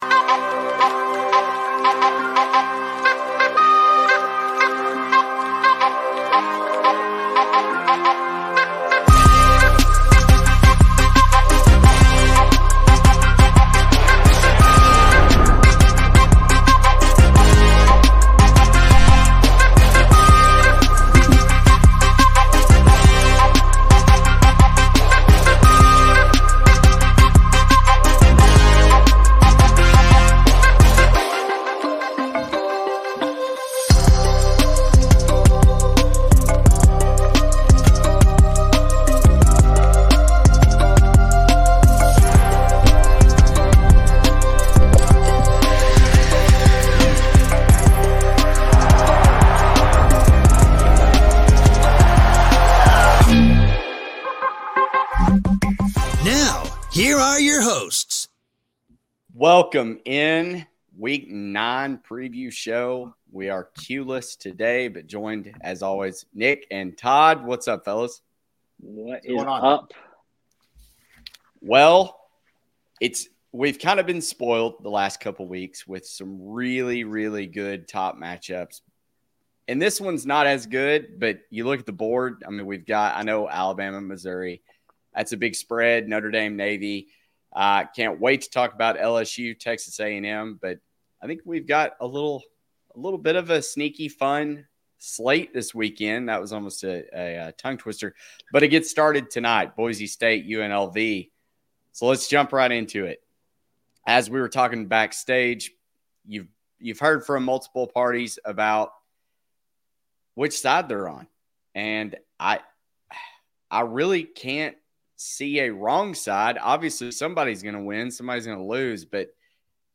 Join us for the live stream of the College Football Insiders Show! Whether you’re here for the analytics and trends, the score projections, or just want to hear some dudes talking ball, we’ve got you covered. In this episode, we’ll dive into the top matchups of Week 9, breaking down each game, plus giving you our best bets.